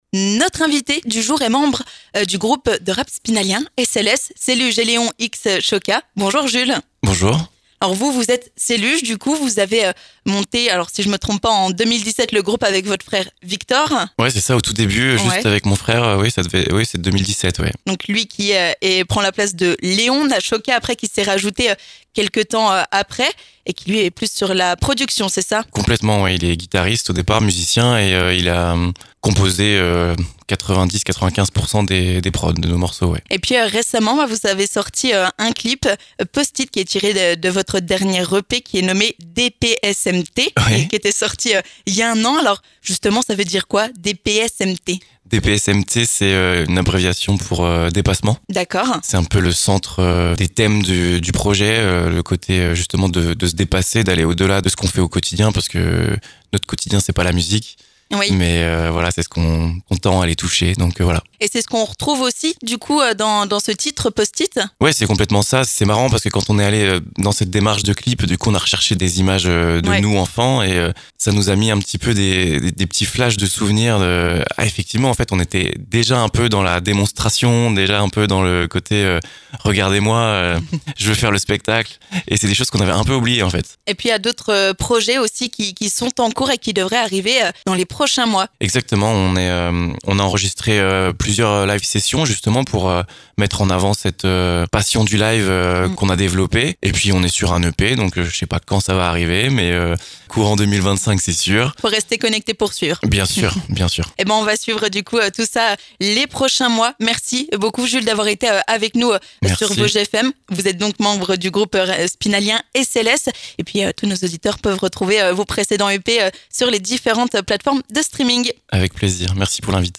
En studio, il revient sur la diffusion de leur dernier clip, "Post-It" tiré de l'EP "DPSMT" sorti il y a un an. Mais aussi sur les projets à venir !